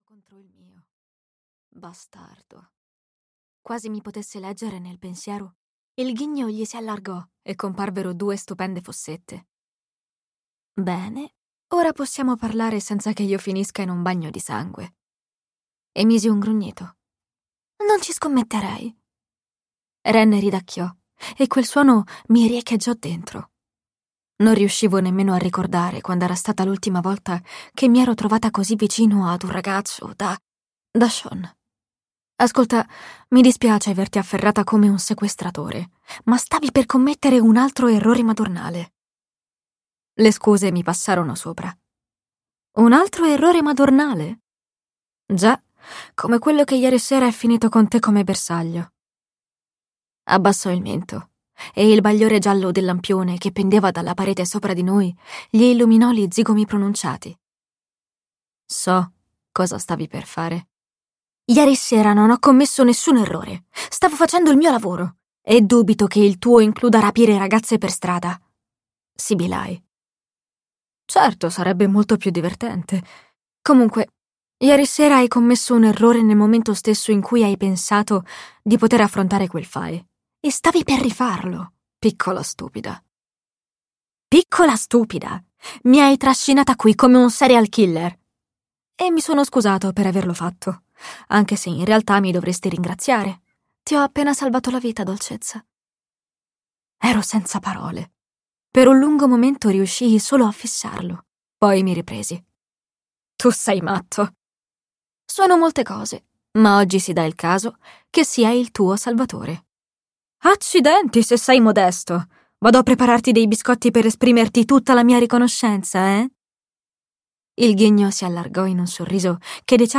Lontano da te" di Jennifer L. Armentrout - Audiolibro digitale - AUDIOLIBRI LIQUIDI - Il Libraio